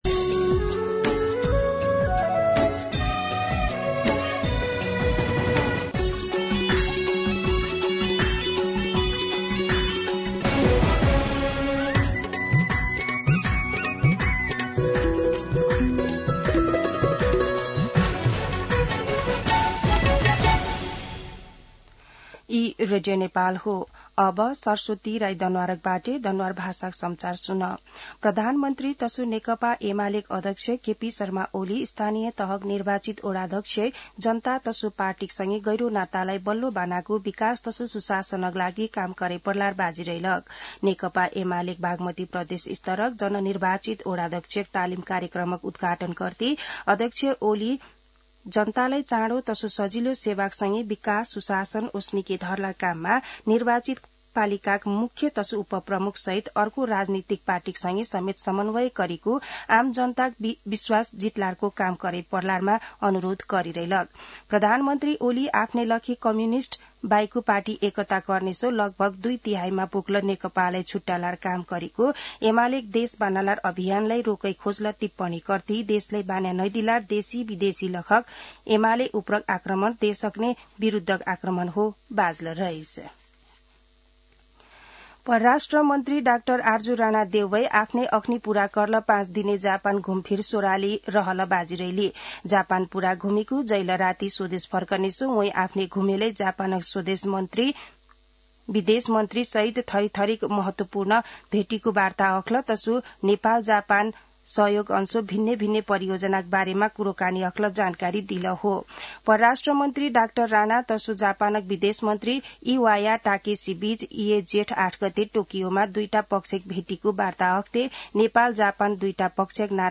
An online outlet of Nepal's national radio broadcaster
दनुवार भाषामा समाचार : ११ जेठ , २०८२